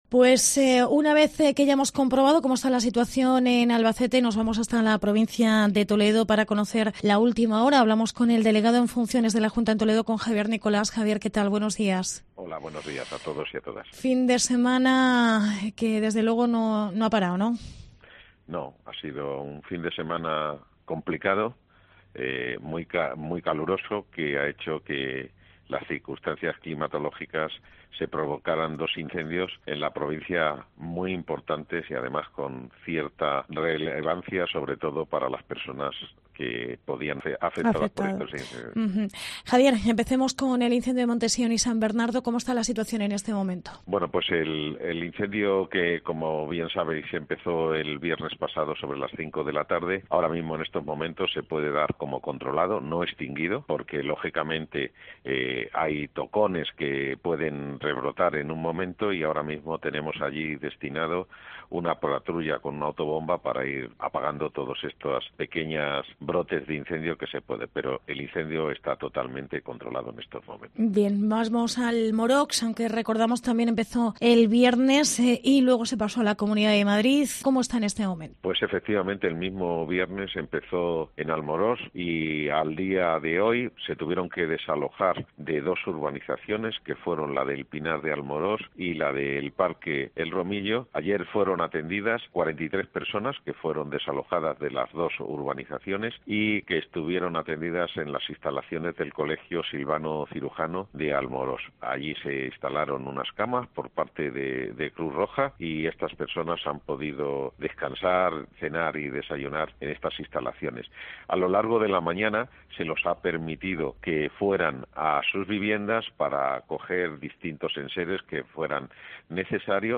Crónica de los incendios de Almorox y Montesión en Toledo. Entrevista Javier Nicolás. Delegado de la Junta